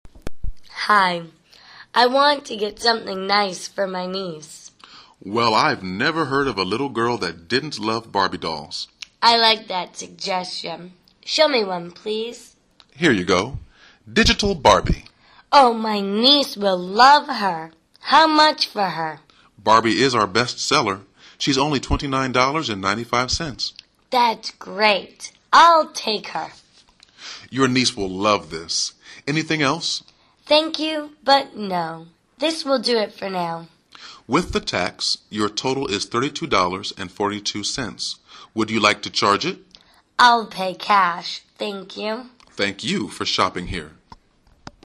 购物英文对话-Looking for a Perfect Gift(9) 听力文件下载—在线英语听力室